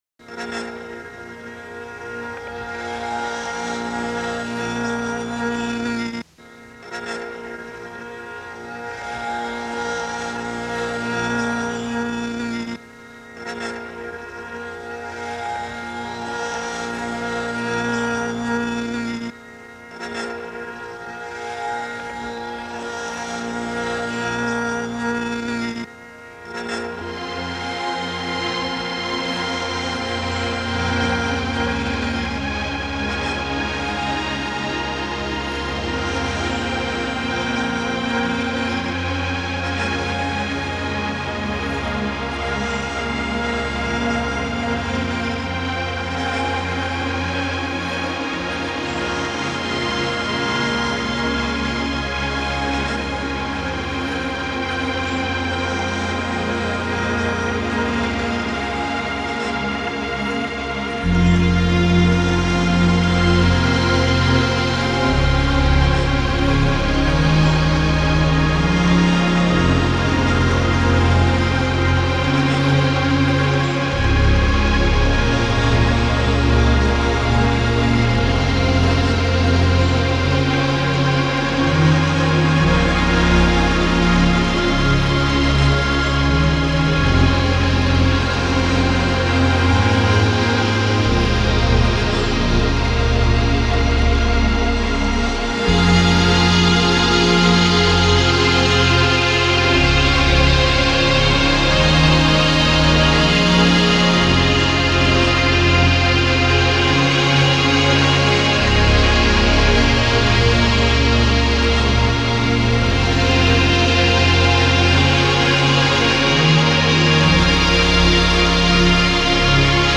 Genre: Downtempo, New Age, Ambient.